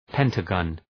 Προφορά
{‘pentə,gɒn}